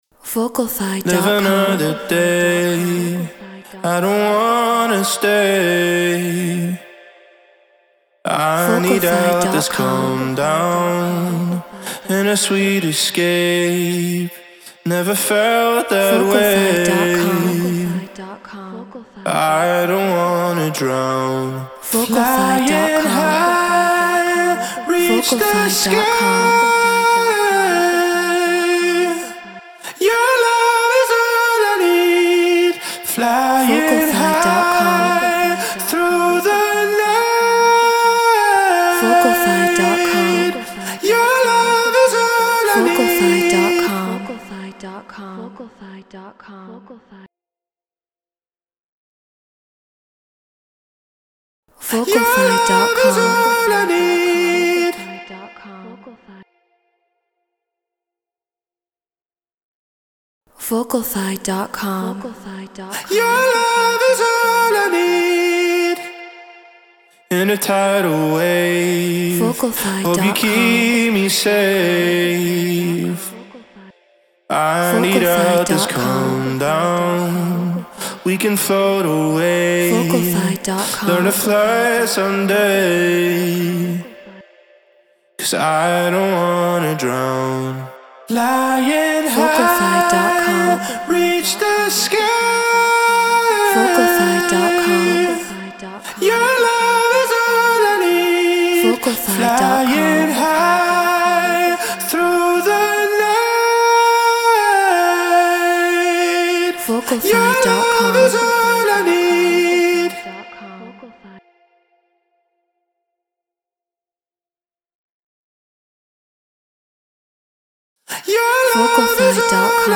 Drum & Bass 174 BPM Fmin
Shure SM7B Apollo Twin X Logic Pro Treated Room